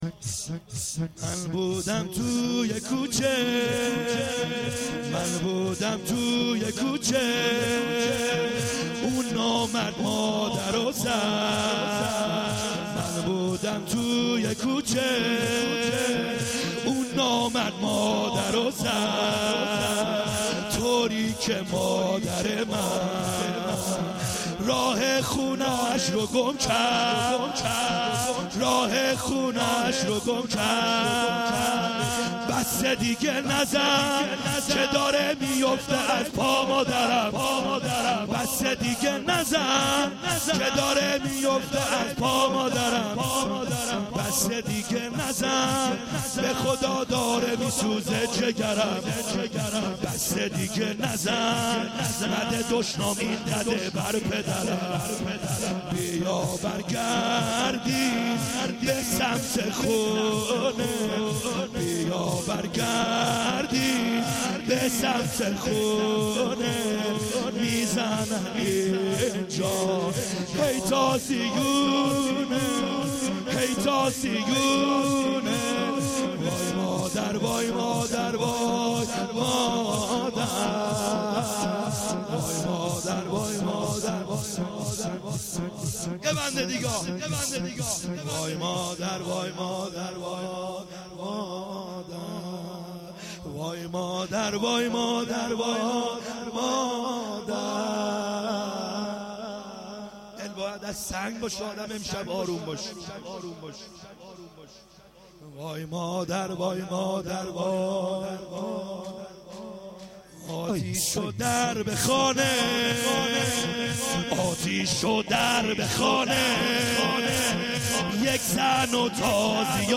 خیمه گاه - بیرق معظم محبین حضرت صاحب الزمان(عج) - لطمه زنی | من بودم توی کوچه